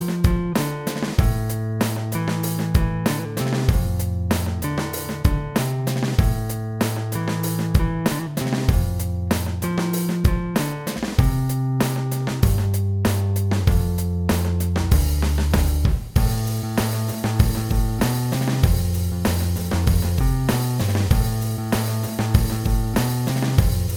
Minus Lead Guitar Rock 5:21 Buy £1.50